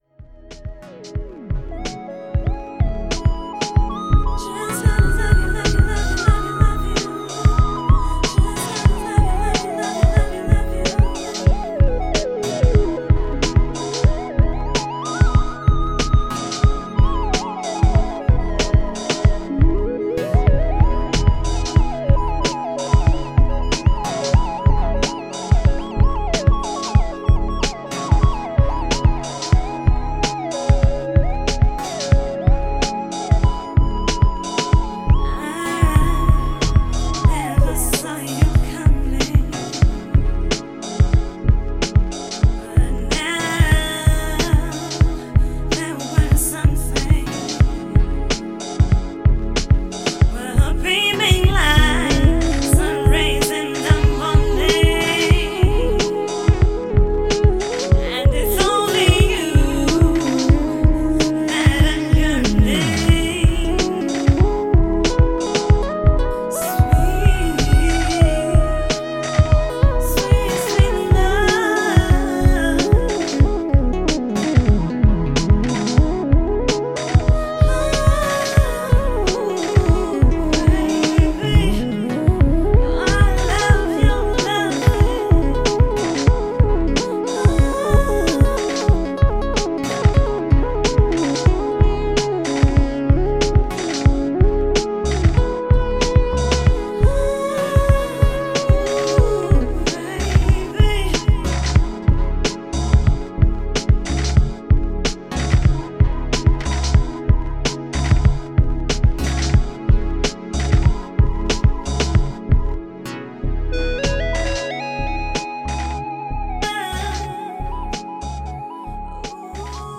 downtempo remix
with heavy Moog work